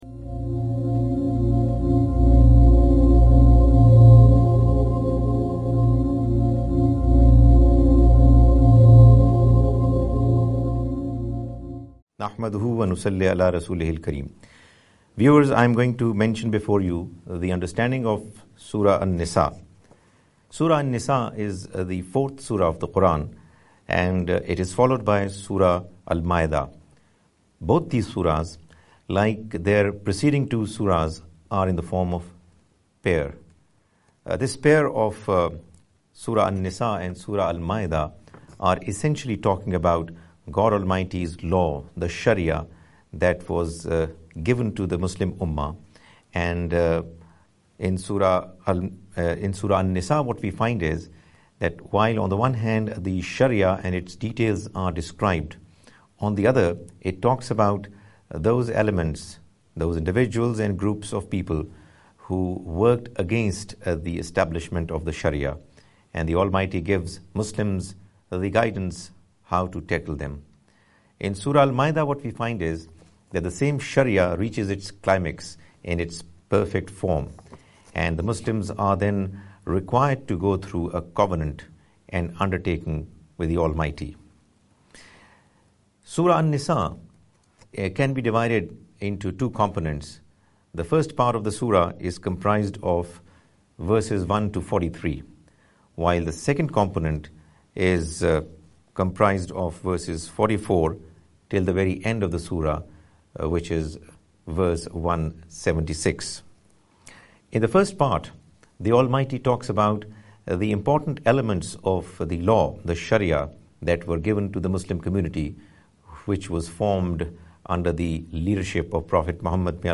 A lecture series